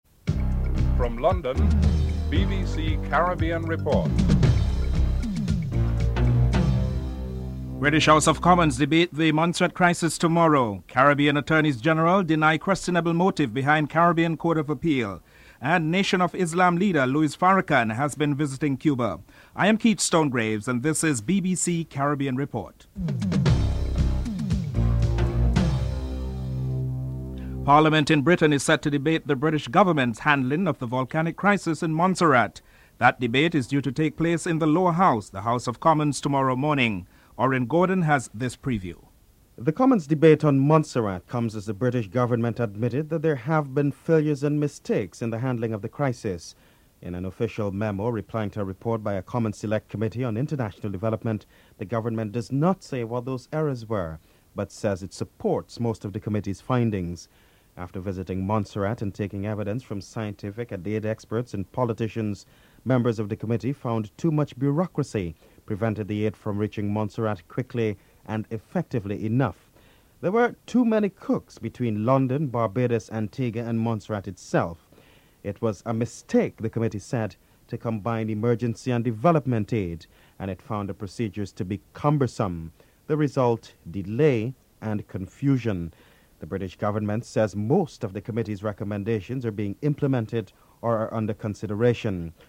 England's captain Mike Atherton describes the intense moments of the match. West Indies Manager Clive Lloyd comments that he expected a better showing from the batsmen (08:05-11:15)
7. Recap of top stories (14:13-14:45)